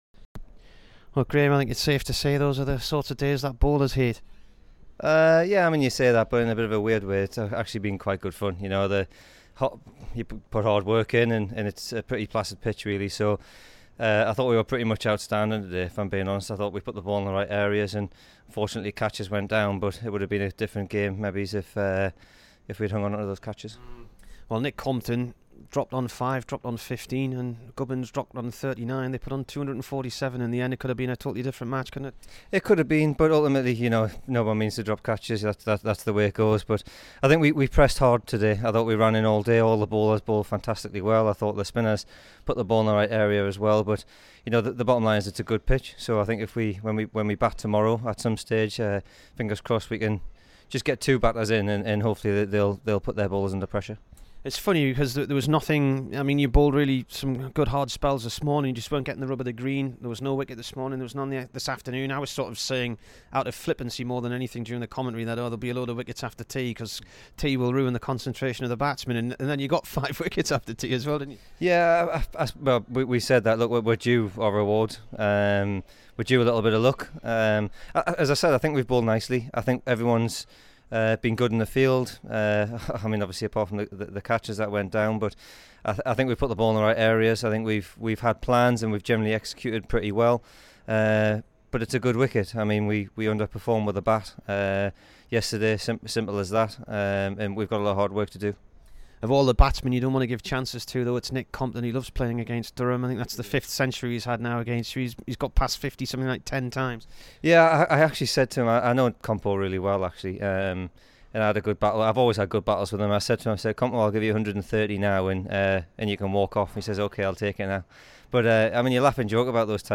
Graham Onions int